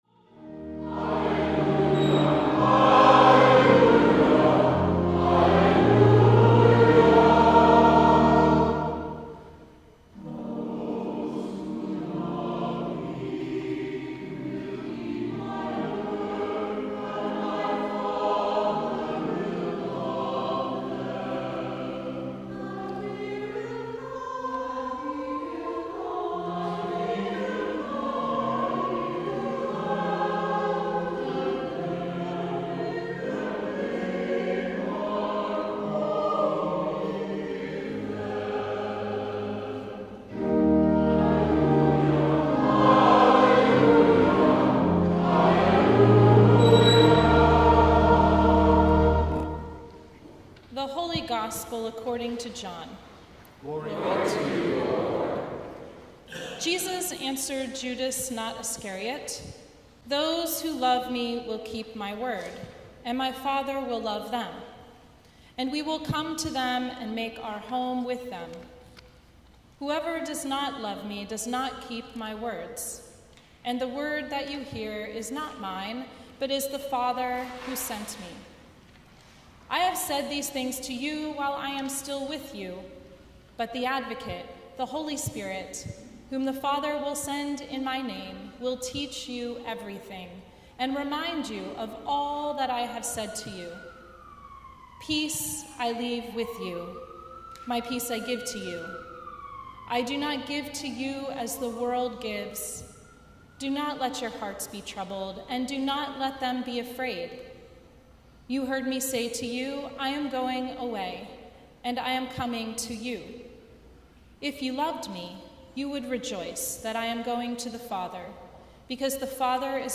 Sermon from the Sixth Sunday in Easter